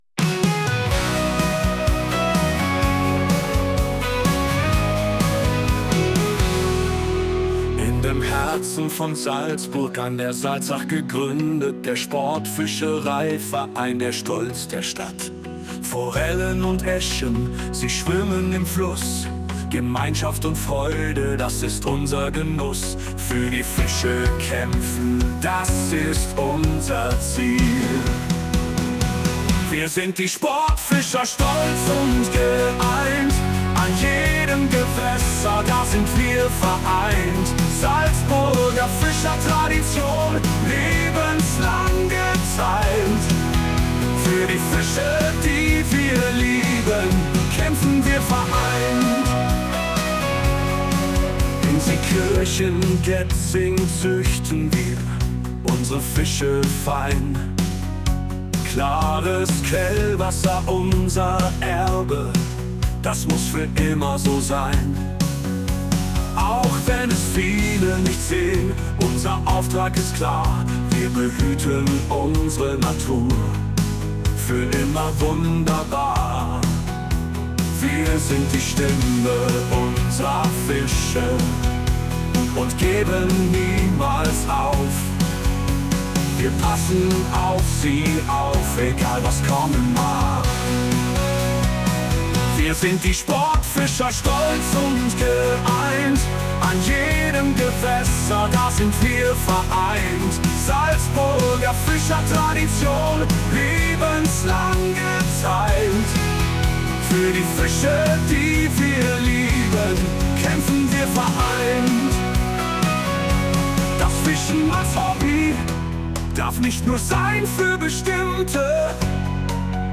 SSFV-Vereinshymne
Die Musik entstand mit digitaler Unterstützung